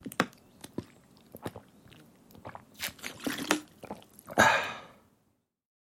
Звуки похмелья
Звук глотков минералки из пластиковой бутылки для быстрого избавления от похмелья